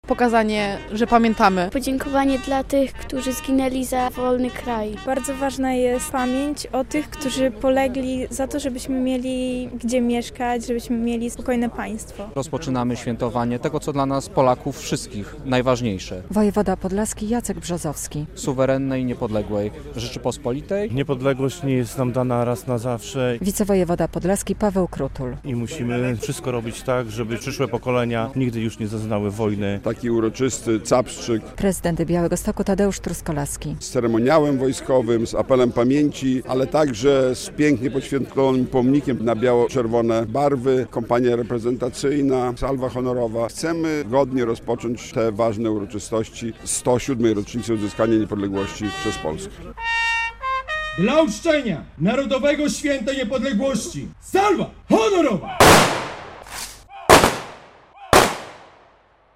Apel poległych i salwa honorowa. Capstrzyk Niepodległościowy w Białymstoku
W przeddzień Święta Niepodległości na Cmentarzu Wojskowym przy ul. 11 Listopada w Białymstoku odbył się uroczysty Capstrzyk Niepodległościowy. Hołd bohaterom, którzy walczyli o Polskę oddali przedstawiciele władz miejskich, wojewódzkich, samorządowych, wojska, policji, straży granicznej i straży pożarnej.